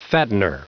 Prononciation du mot fattener en anglais (fichier audio)
Prononciation du mot : fattener